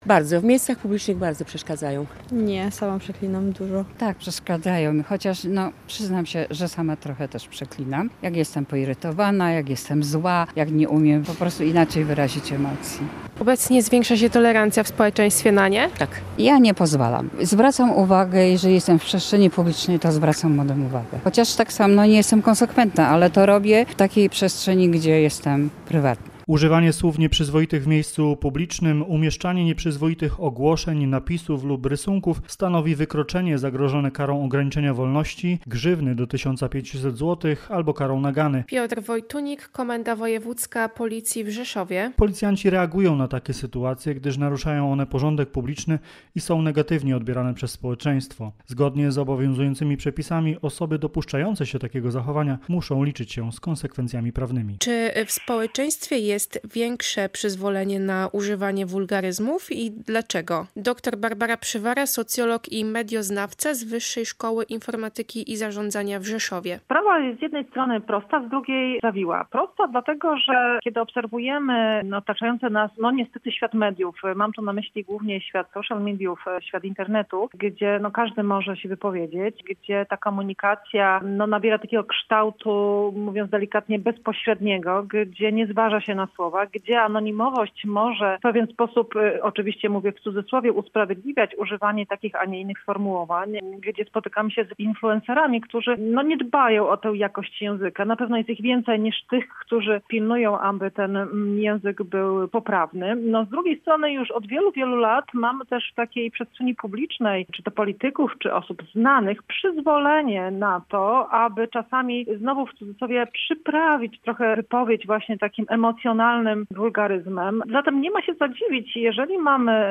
Opinie mieszkańców Rzeszowa są podzielone – jedni przyznają, że wulgaryzmy im przeszkadzają, inni mówią, że sami po nie sięgają, zwłaszcza w emocjach.